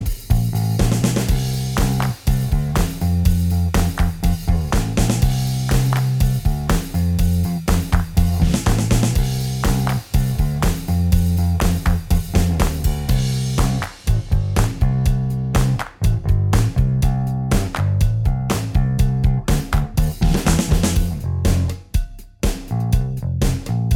Minus Guitars Rock 3:34 Buy £1.50